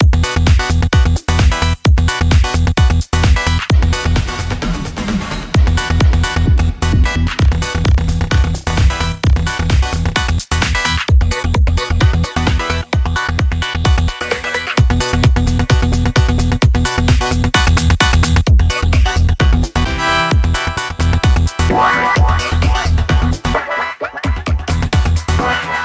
Sugar Bytes Effectrix 2 是一款专业的多效果音序器，可以实时地对音频进行创意的处理和变形。它有 14 种高品质的效果，从经典的时间拉伸和循环到颗粒合成和故障疯狂。